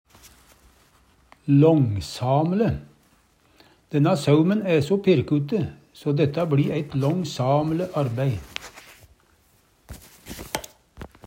långsam'le - Numedalsmål (en-US)